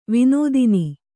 ♪ vinōdini